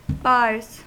Ääntäminen
IPA : /ˈbɑː(ɹ)z/